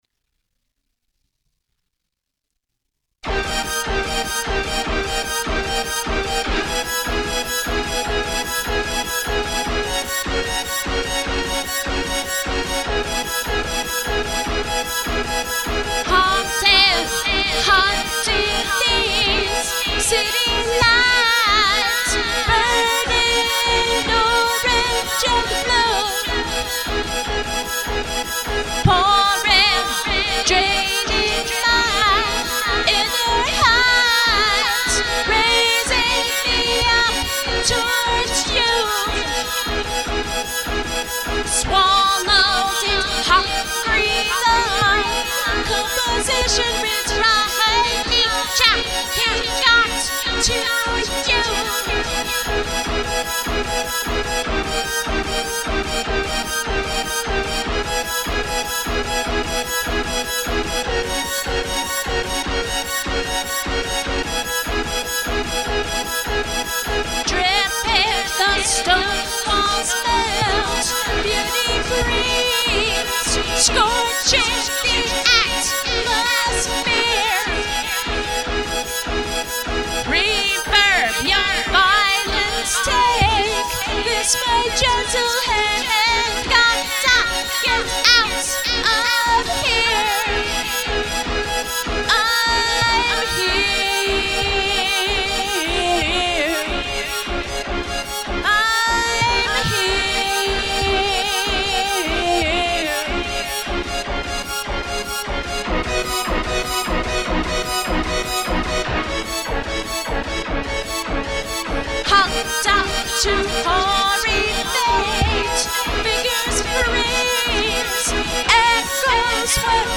The Orange Atmosphere (voice/electronic)